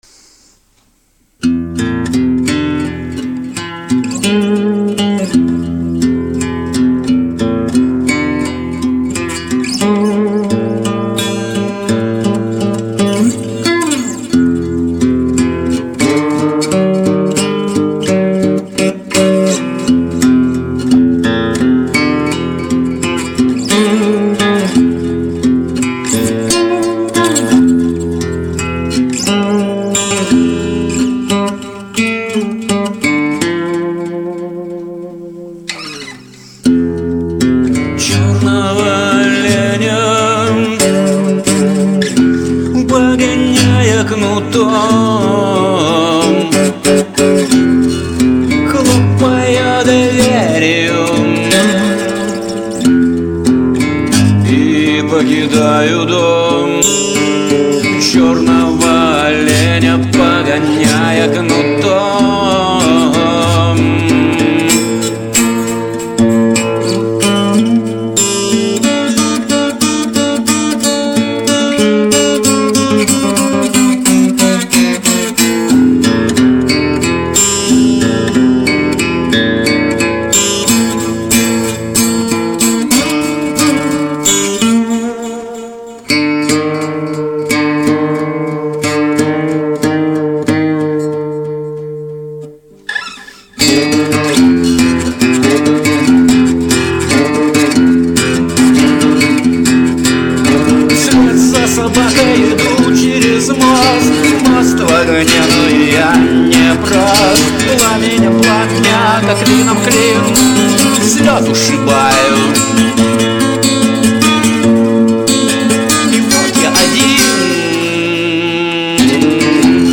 • Жанр: Блюз